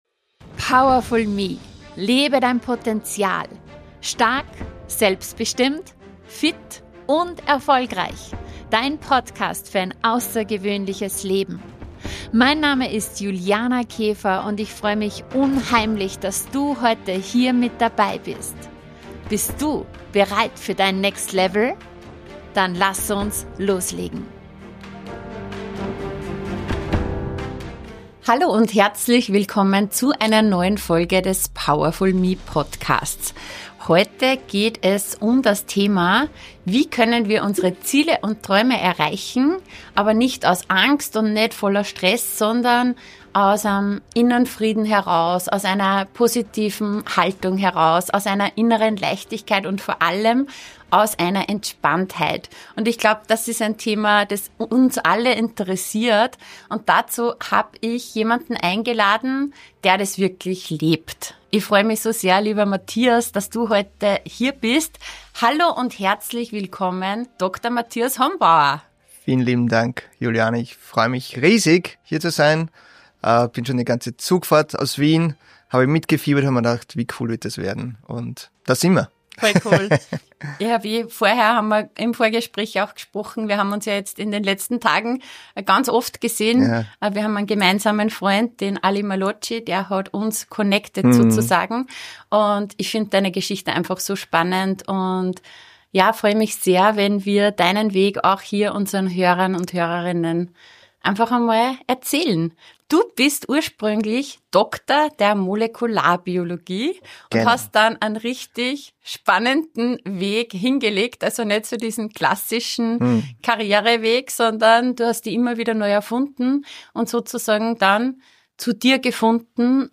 Darum geht’s: • Warum mehr Push oft weniger bringt • Wie innere Ruhe Leistung verändert • Entscheidungen aus Intuition statt Angst • Erfolg ohne Ausbrennen • Wer du wirst auf dem Weg zu deinen Zielen Ein Gespräch für alle, die viel erreichen wollen - aber nicht um jeden Preis.